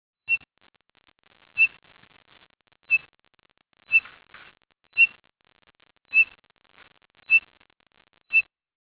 Myadestes townsendi (Townsend's solitaire)
Townsend's Solitaires (Myadestes townsendi) like the brushy canyons of Central Arizona in the Winter, especially. They call this call note from the top of a handy juniper or mesquite.